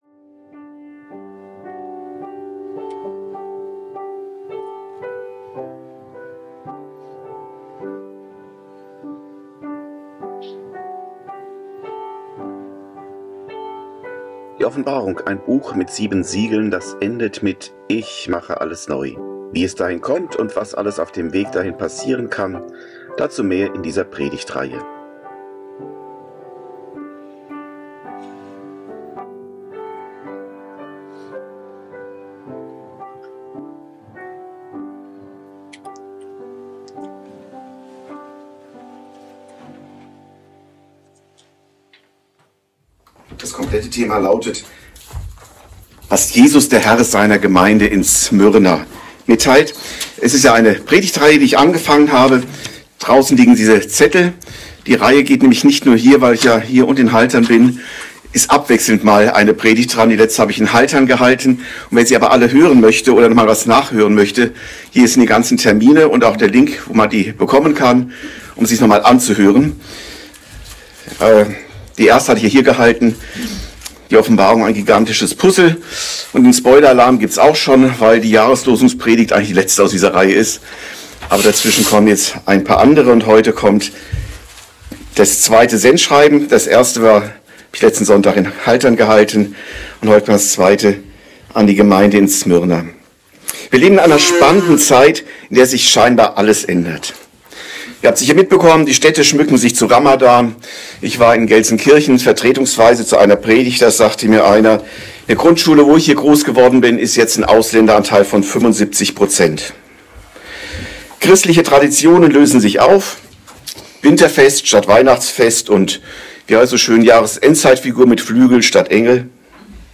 Predigt
gehalten in der EFG NOrdhorn Folge direkt herunterladen